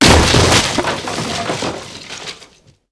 劈劈柴2－YS070511.wav
通用动作/01人物/06工作生产/劈劈柴2－YS070511.wav
• 声道 立體聲 (2ch)